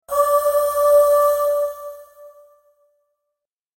穏やかな女性のハミングが奏でるメロディが、通知音として心を癒します。